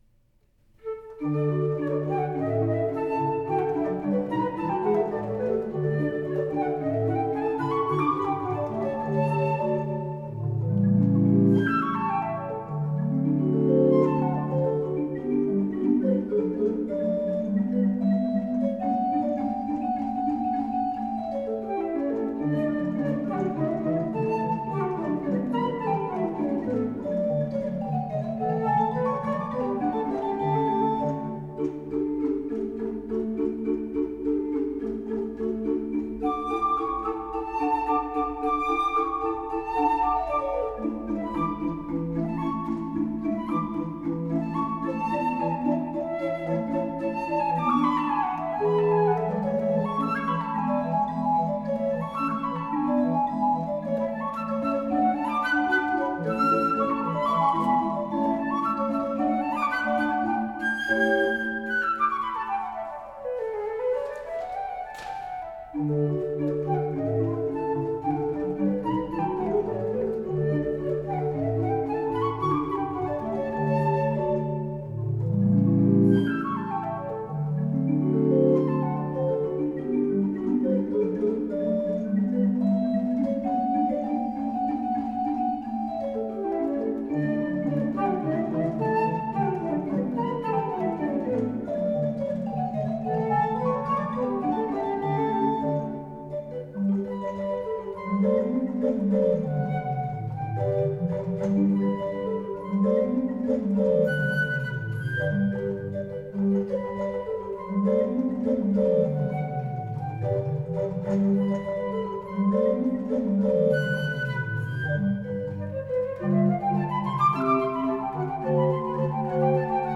Ludwig van Beethoven Aus der Serenade op.41 für Flöte und Orgel Allegro vivace e disinvolto